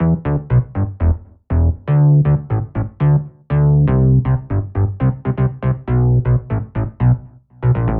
34 Bass PT1.wav